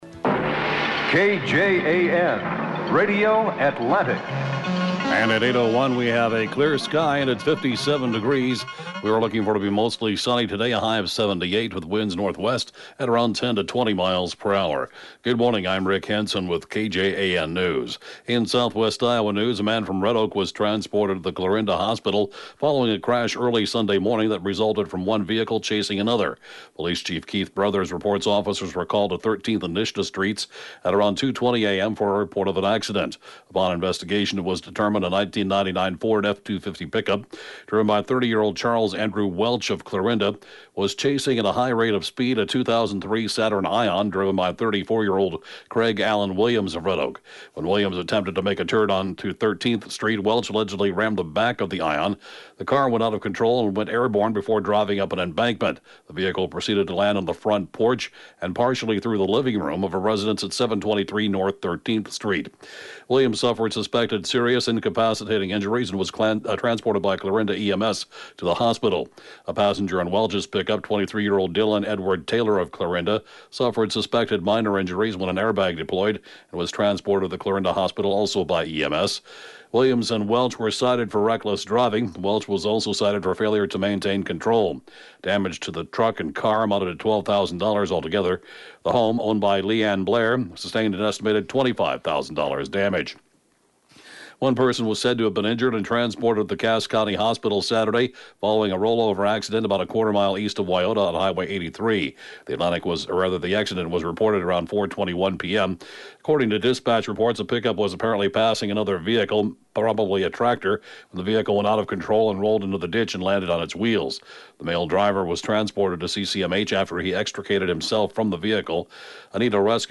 (Podcast) KJAN Morning News & Funeral report, 7/18/2019